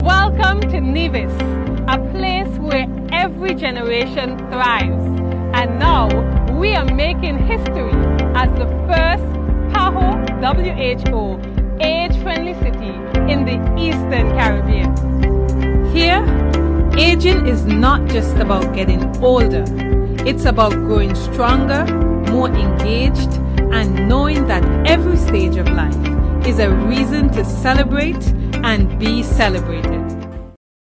This is Minister of, Social Empowerment, et. al., in Nevis, the Hon. Jahnel Nisbett: